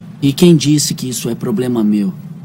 Categoria: Sons virais